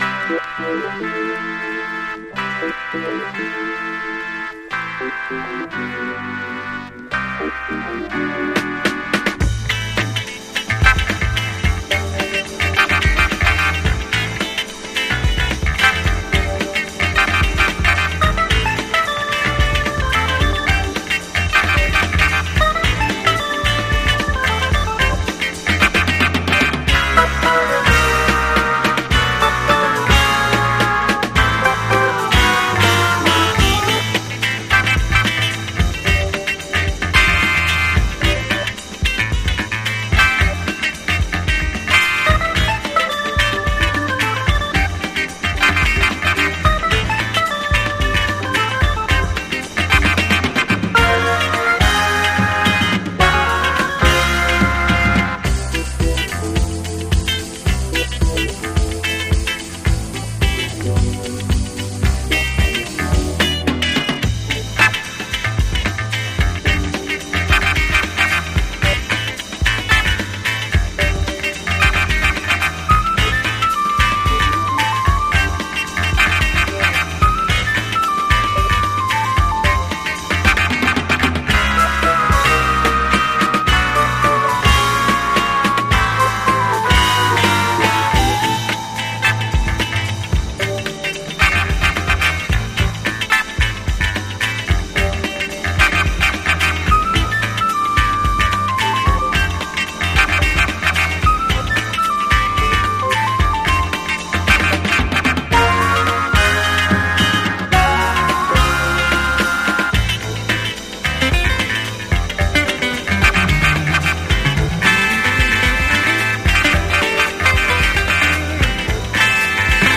ソリッドに弾ける洒脱でキラーなフレンチ・ジャズ・ファンク！
BASS
DRUMS
GUITAR
KEYBOARDS